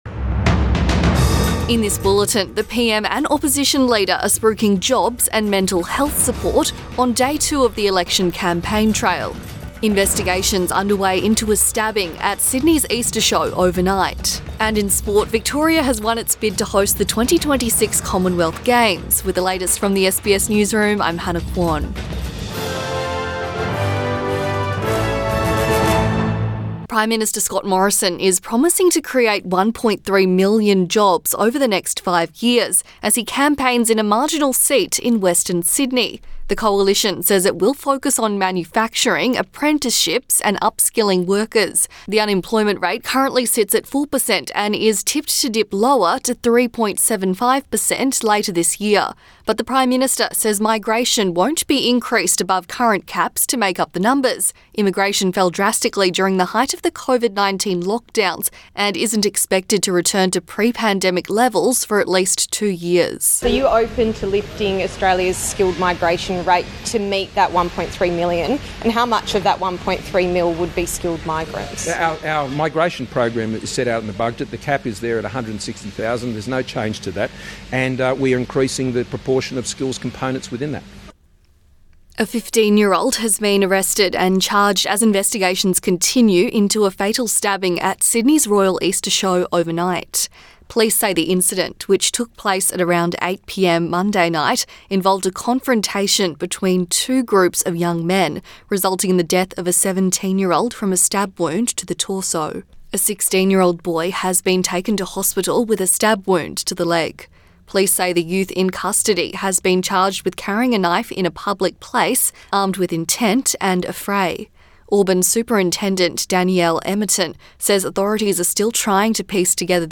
Midday bulletin 12 April 2022